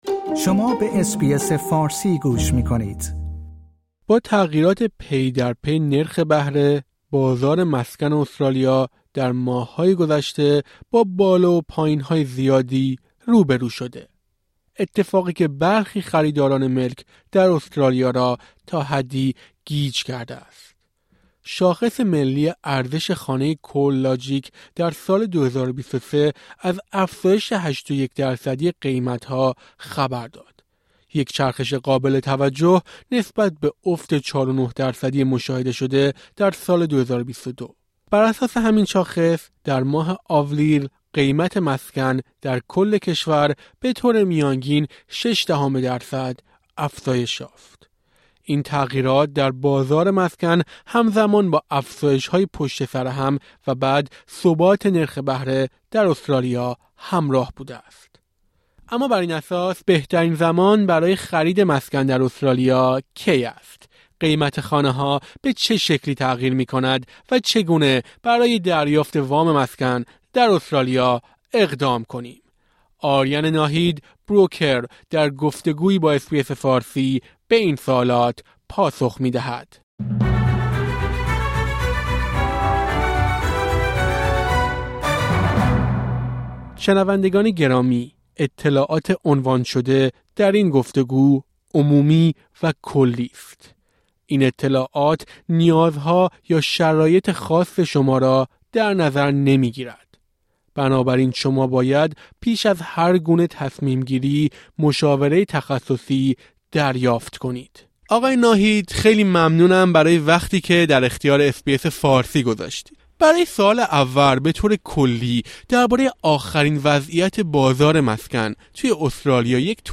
بروکر (broker) در گفت‌وگو با اس‌بی‌اس فارسی به این سوالات پاسخ می‌دهد.